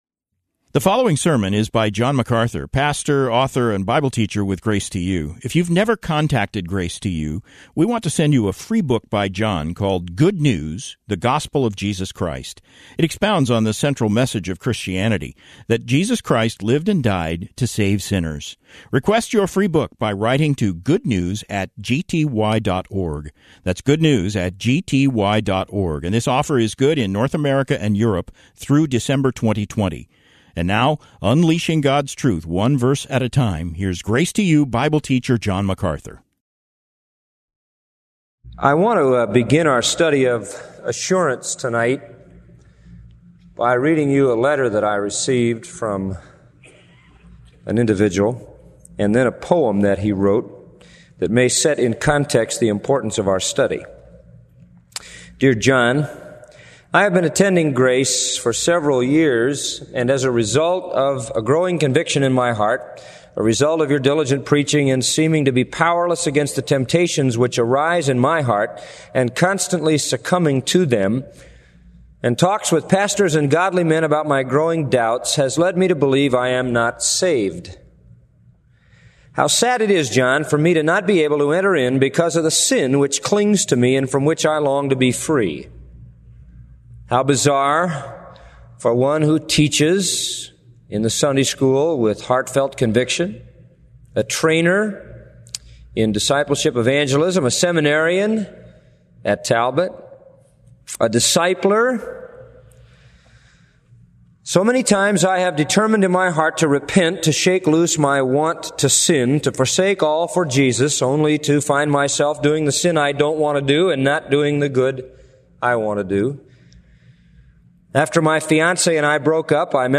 SERMON: